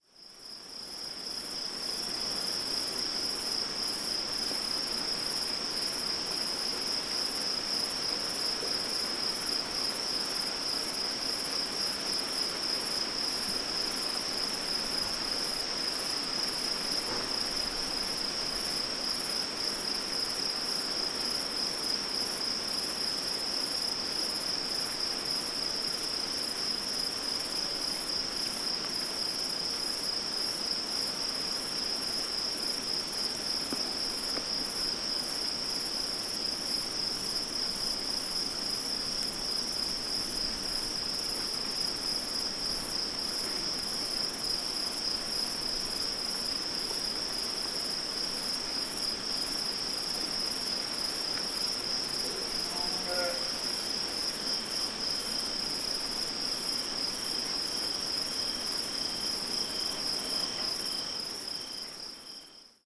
Ambiente de campo con grillos 1
ambiente
grillo
Sonidos: Animales
Sonidos: Rural